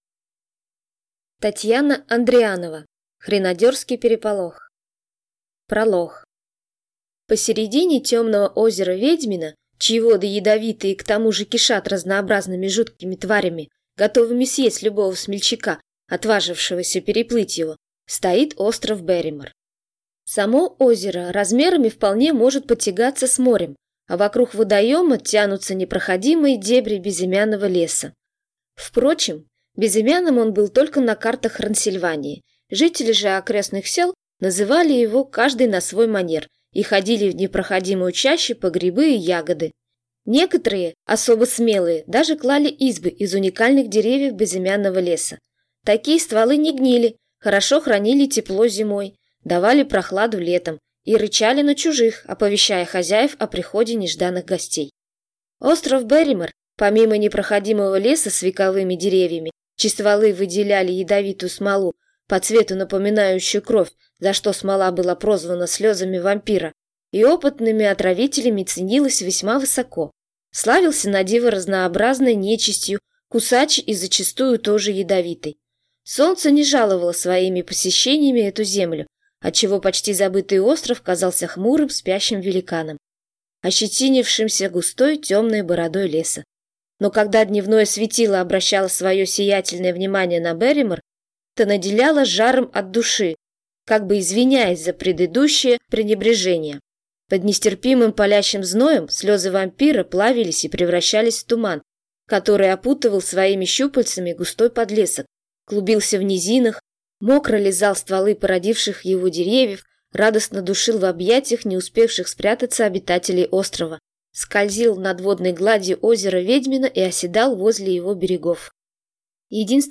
Аудиокнига Хренодерский переполох | Библиотека аудиокниг